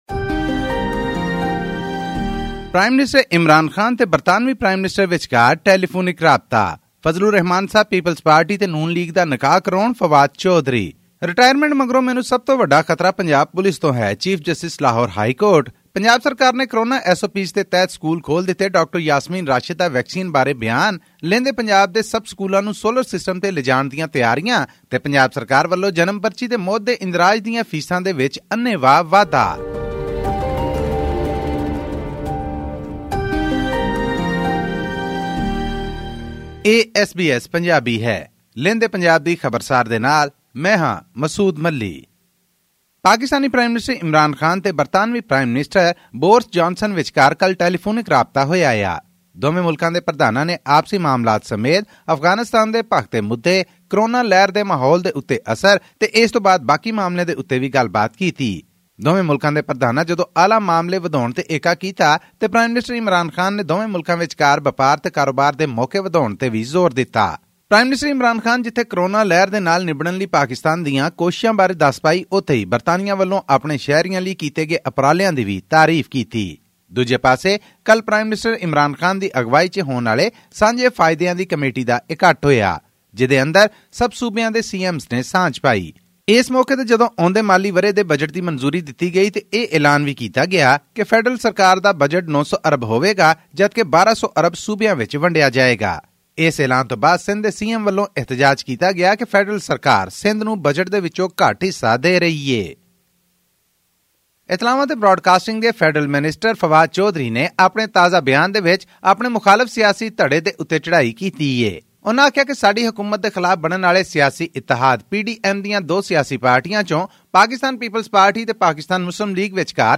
Prime Minister Imran Khan on 7 June held a telephonic conversation with his UK counterpart Boris Johnson and exchanged views on the Afghan peace process, COVID-19 pandemic, and climate change. All this and more in our weekly news bulletin from Pakistan.